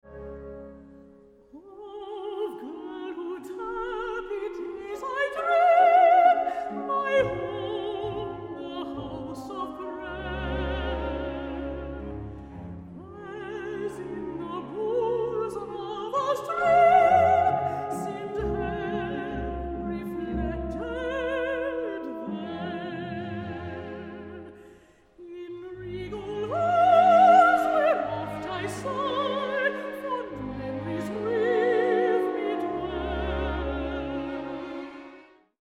Arias from British Operas